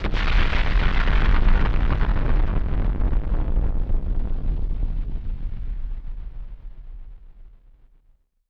BF_DrumBombC-03.wav